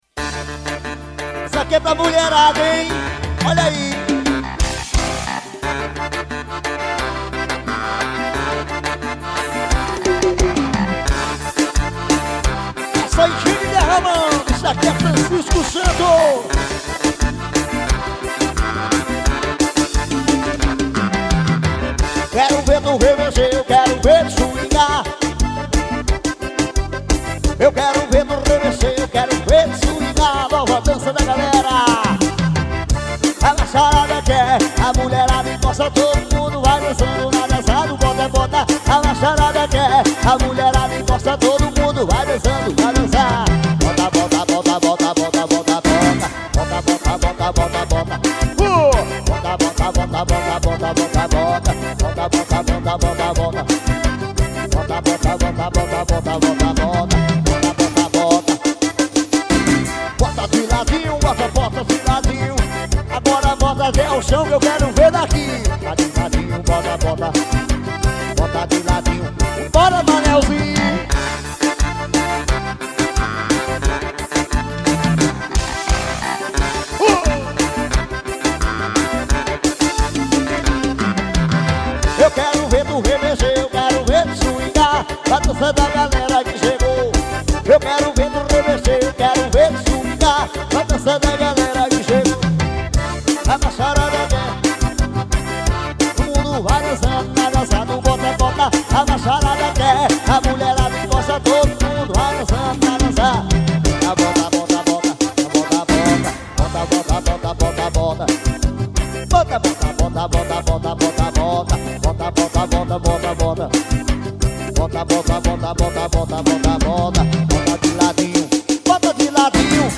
AOVIVO EM INHUMA.